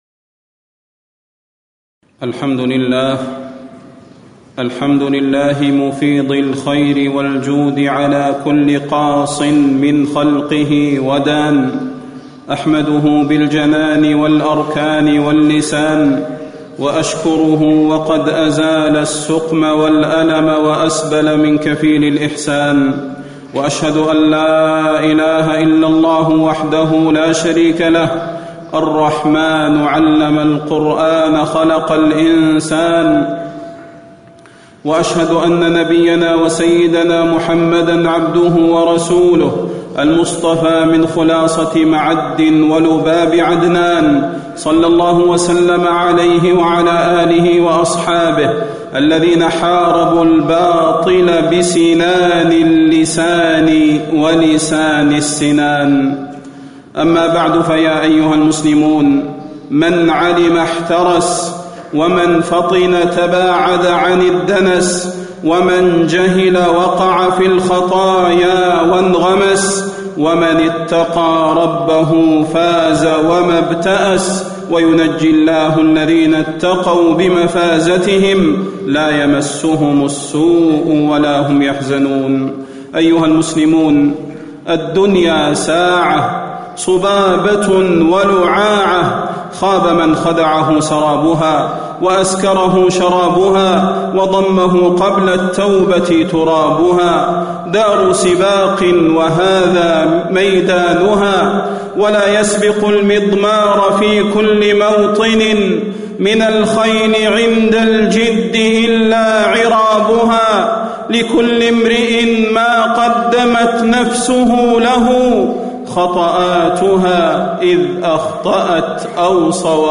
تاريخ النشر ٢٦ ربيع الثاني ١٤٣٧ هـ المكان: المسجد النبوي الشيخ: فضيلة الشيخ د. صلاح بن محمد البدير فضيلة الشيخ د. صلاح بن محمد البدير التحذير من الغلو والإنحراف